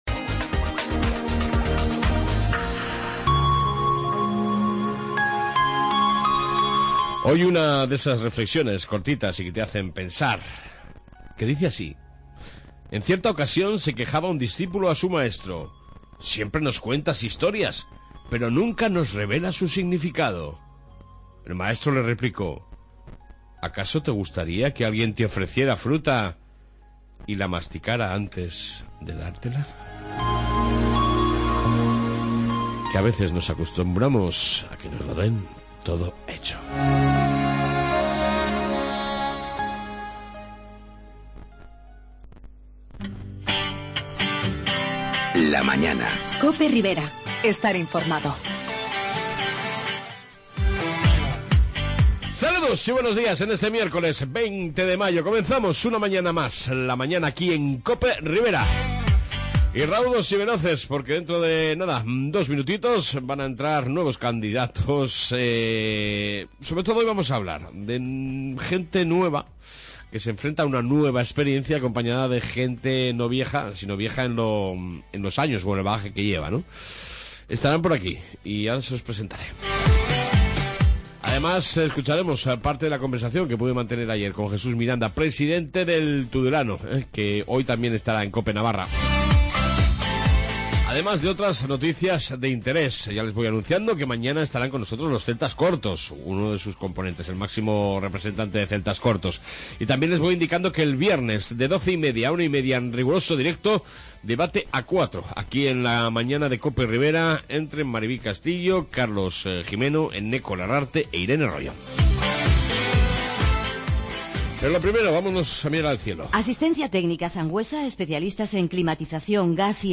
AUDIO: Hoy han visitado nuestros estudios nuevas caras de UPN junto con otras que llevan ya un amplio bagaje en el mundo de la política...hoy hemos...